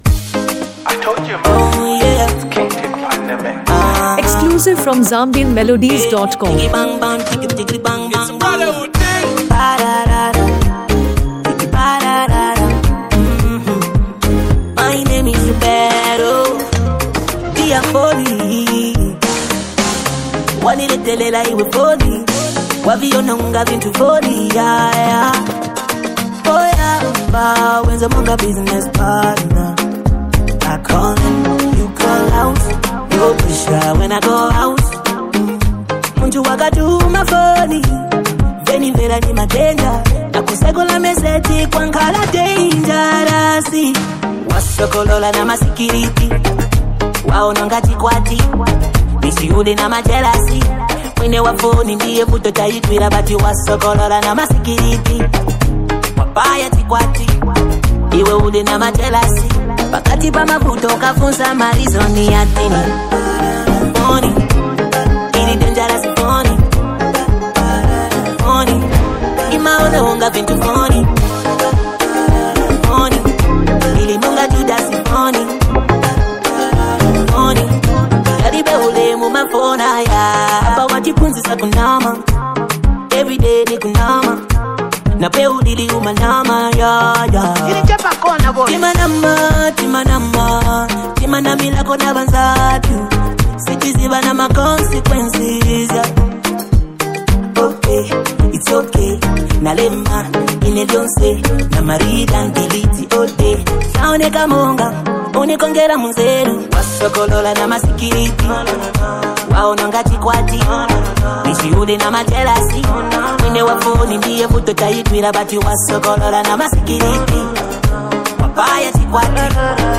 Afro-pop and R&B
In this mid-tempo Afro-R&B tune
clean production
soulful sound